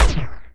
poly_shoot02.wav